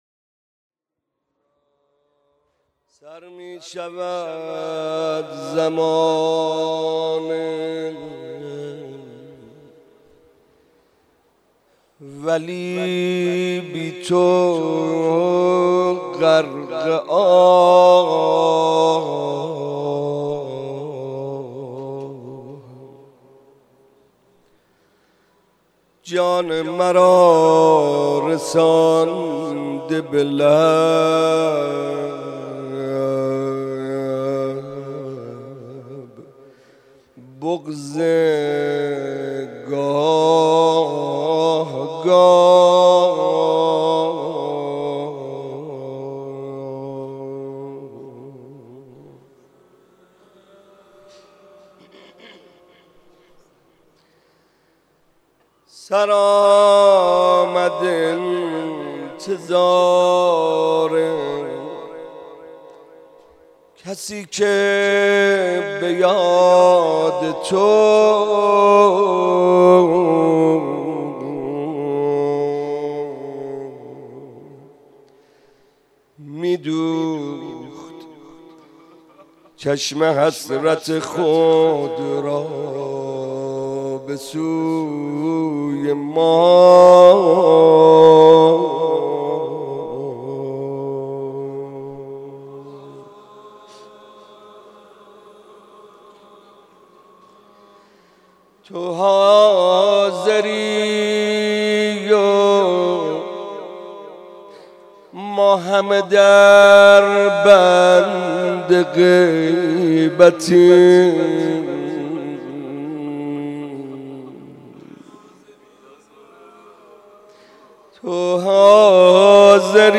روضه ظهر عاشورا